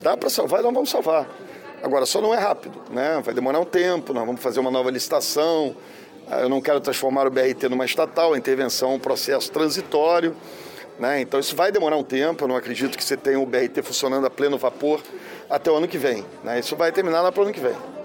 Prefeito participou de almoço com empresários em hotel da Zona Sul